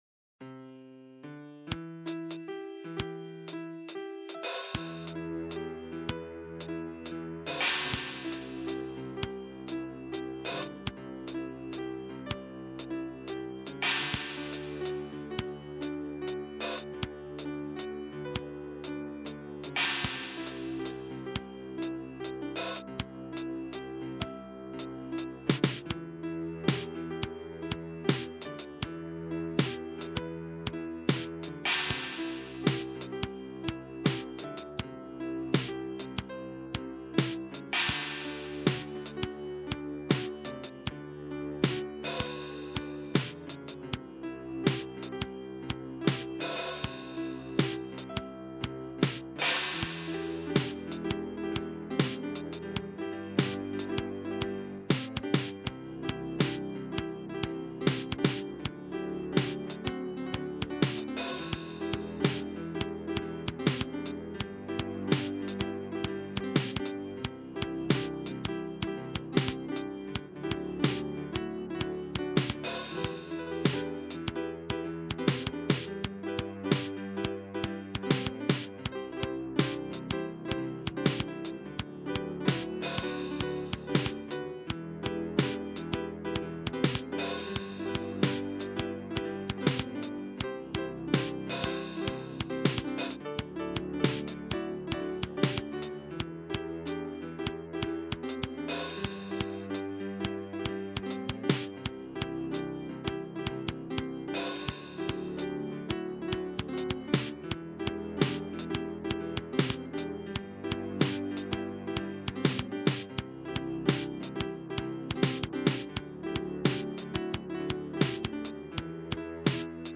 Simple Melody and Chords with Drums.